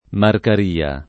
Marcaria [ markar & a ]